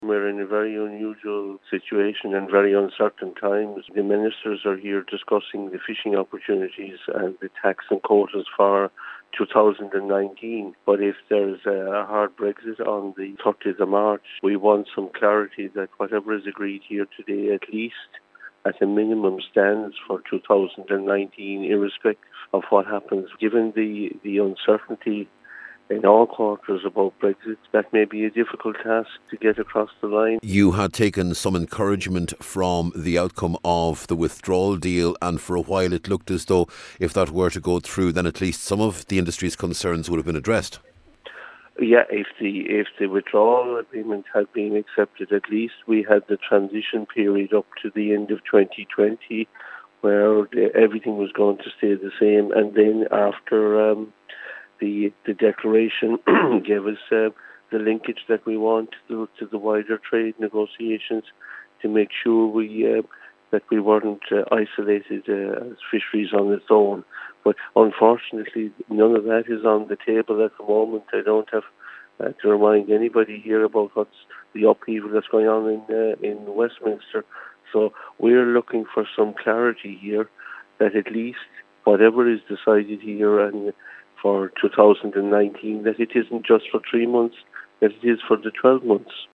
speaking in Brussels ahead of the annual meeting of the Council of Fisheries Ministers which gets underway today.